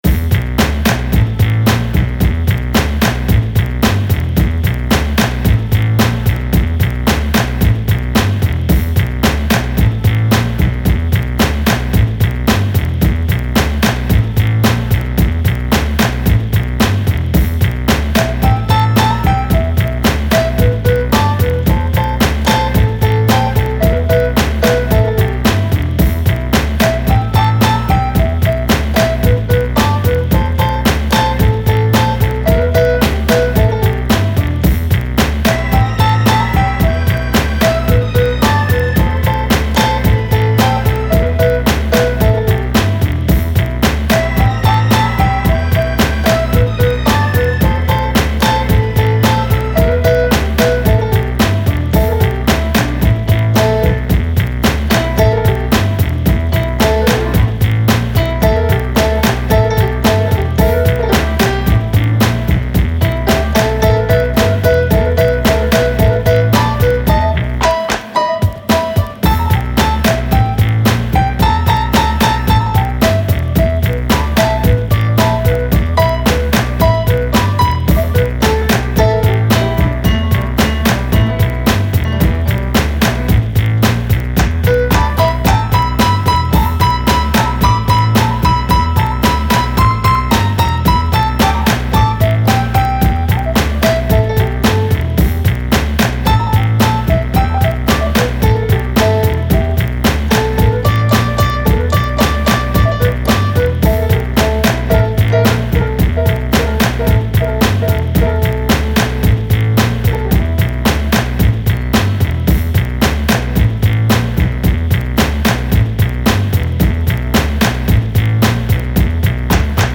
Bass-heavy catchy retro groove with jazzy piano freestyle.